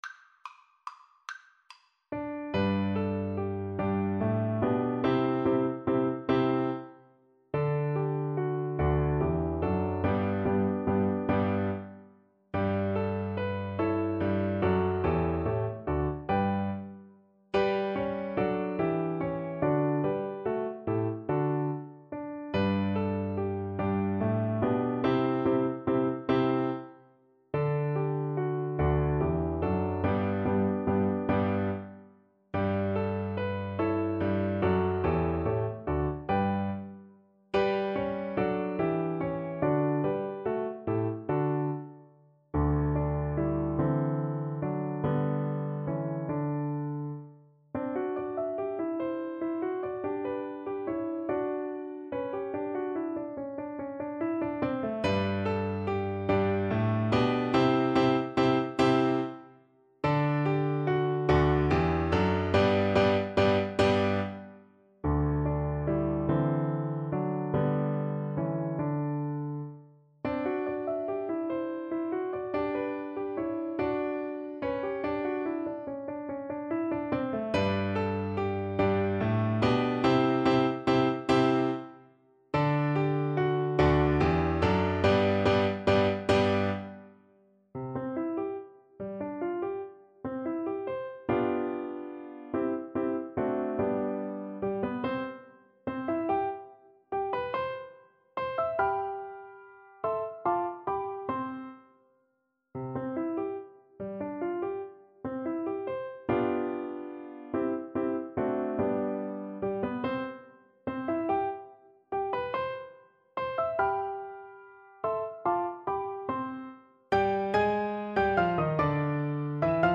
3/4 (View more 3/4 Music)
Moderato =c.144
Classical (View more Classical Flute Music)